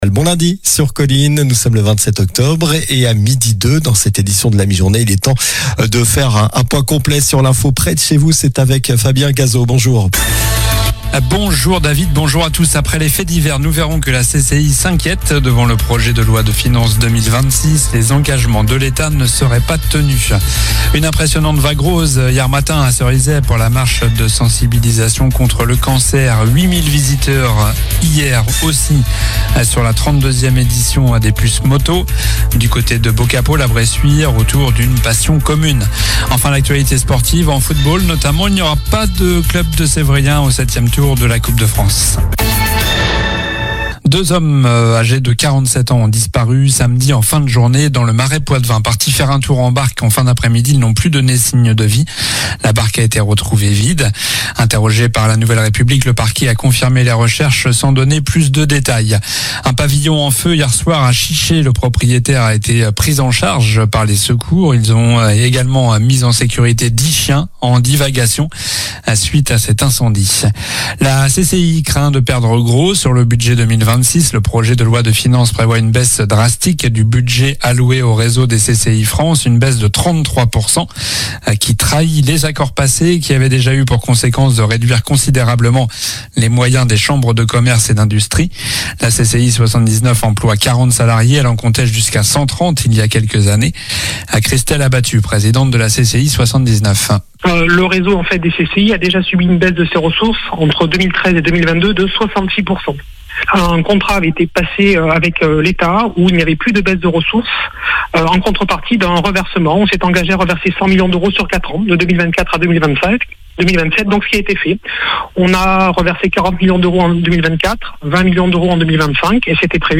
Journal du lundi 27 octobre (midi)